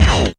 NOISY STOP 2.wav